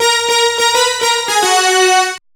Synth Lick 50-04.wav